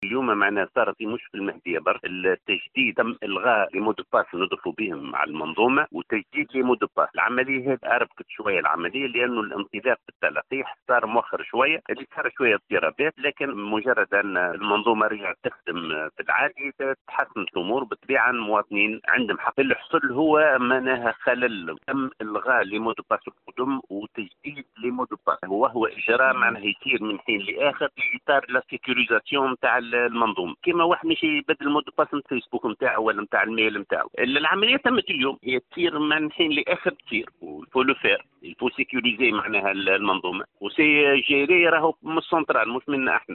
المدير الجهوي للصحة بالمهدية يوضح سبب تعطل عمليات التلقيح اليوم (تسجيل) - Radio MFM
سجلت مختلف مراكز التلقيح بكافة ولايات الجمهورية اليوم، خللا تقنيا تمثل في تغيير كلمة السر الخاصة بمنظومة التلقيح، ما تسبب في تعطل المنظومة سويعات من اليوم، وهو ما انجر عنه احتقان واكتظاظ في صفوف المواطنين. وأوضح المدير الجهوي للصحة بالمهدية عمر بن منصور، في تصريح ل “ام اف ام”، أن هذه العلمية هي حينية بهدف حماية المنظومة وتأمينها وتم إصلاح الخلل من الإدارة المركزية، واستئناف عملية التلقيح بطريقة طبيعية.